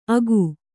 ♪ agu